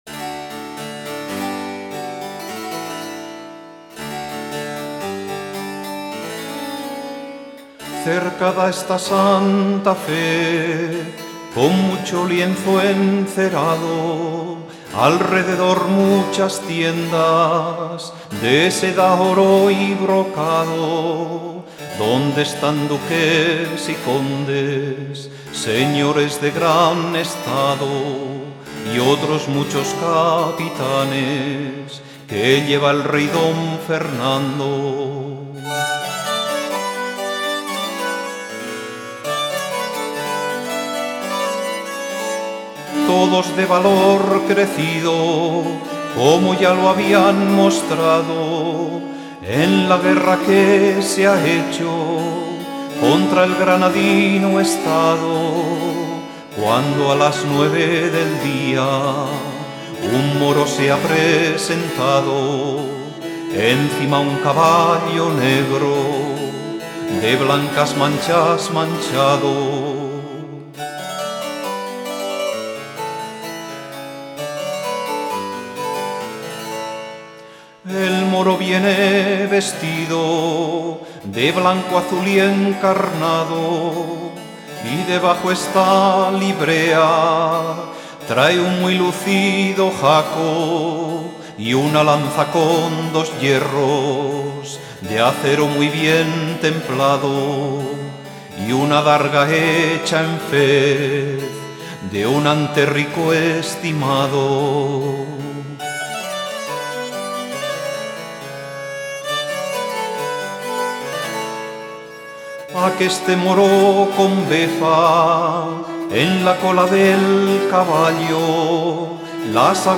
Intérpretes: Voz: Joaquín Díaz